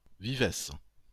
Vivès (French pronunciation: [vivɛs]